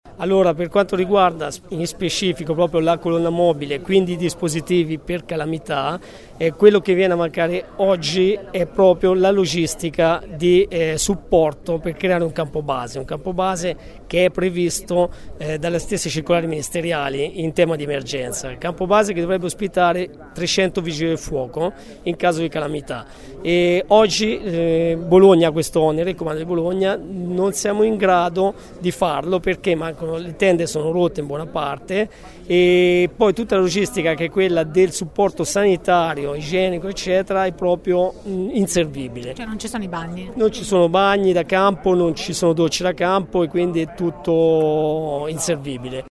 vigile del fuoco